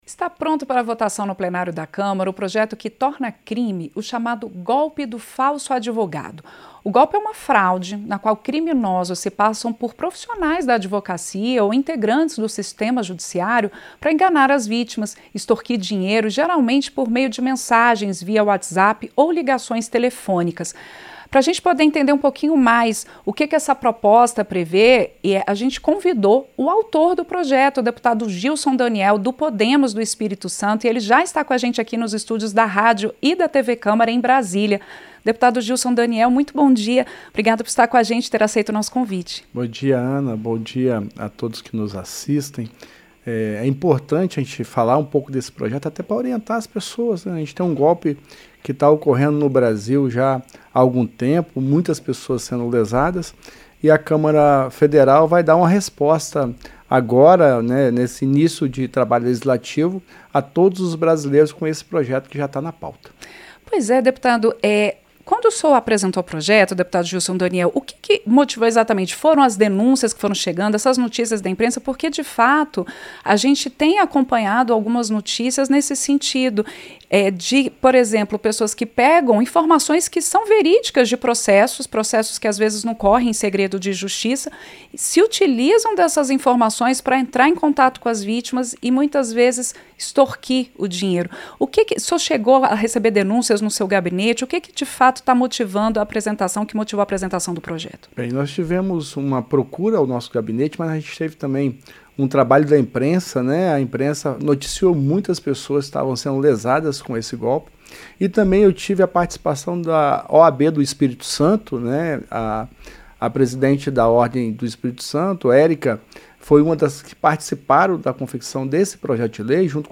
Entrevista -Dep. Gilson Daniel (Podemos-ES)